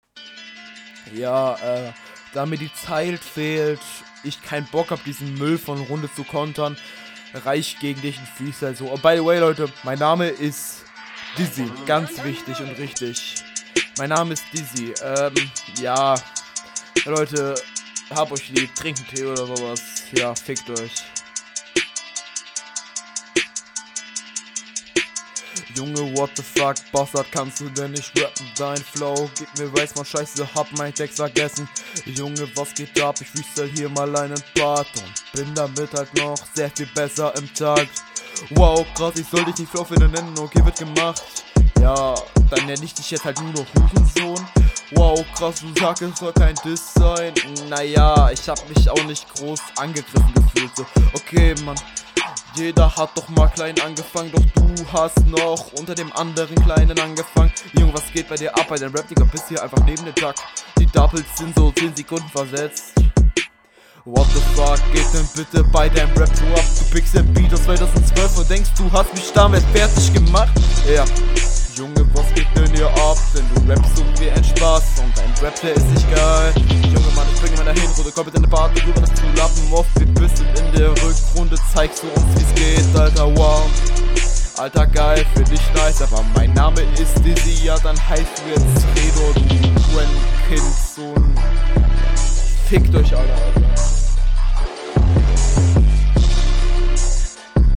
Gesamteindruck: 1/10 Hast jetzt einfach einen Freestyle gedroppt ok, kann man machen, muss man aber …